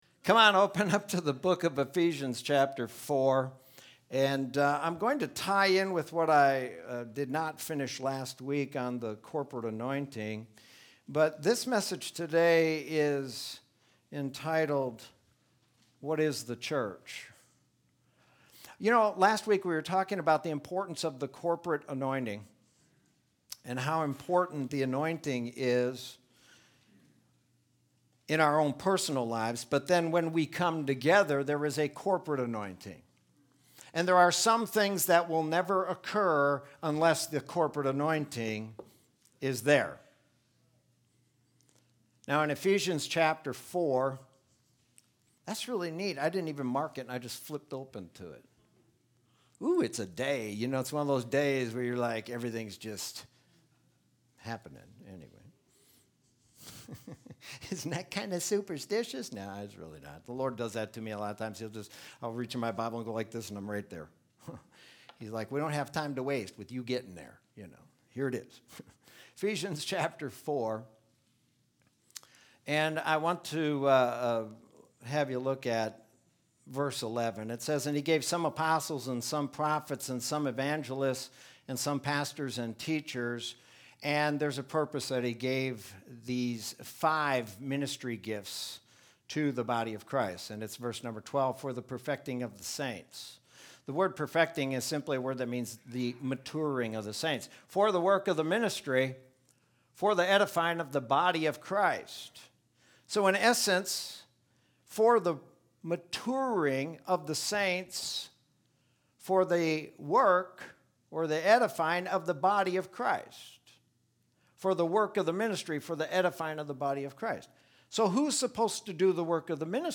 Sermon from Sunday, September 13th, 2020.